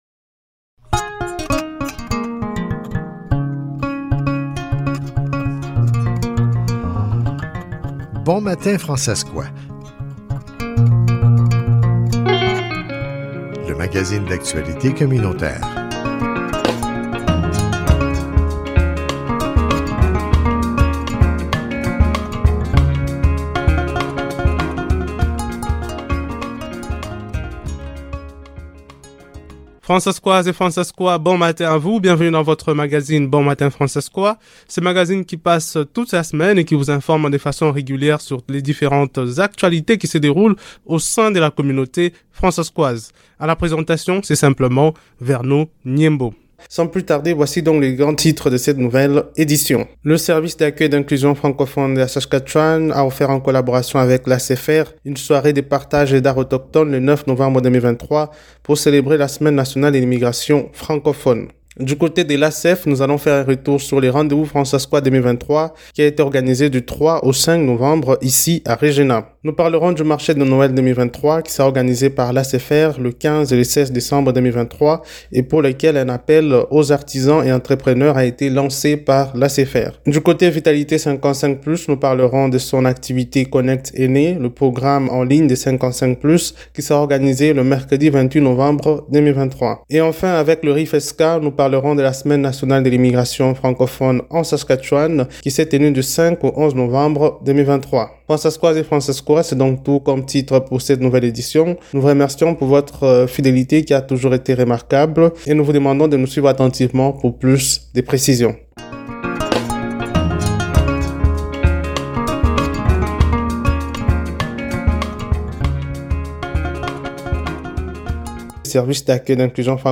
le discours d'ouverture